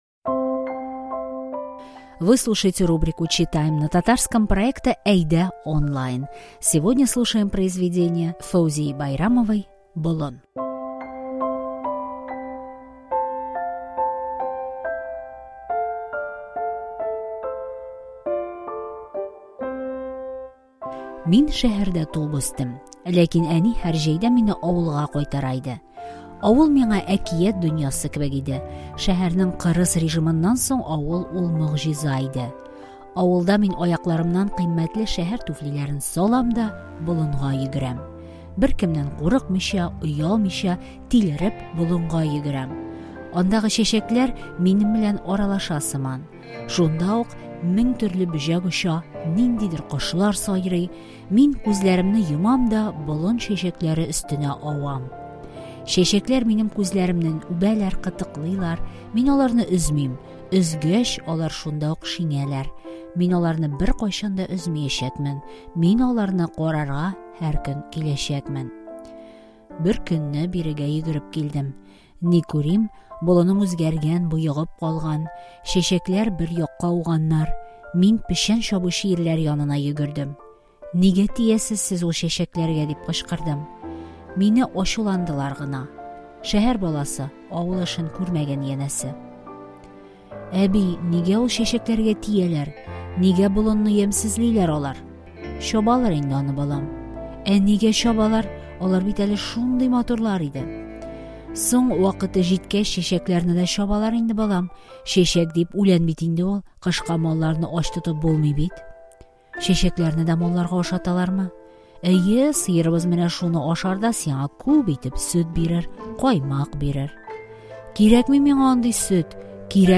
Читаем красивое произведение современной татарской писательницы, общественного деятеля – Фаузии Байрамовой. Изданная в 1986 году повесть "Болын" раскрывает красоту и богатство татарского языка и подкупает своей искренностью.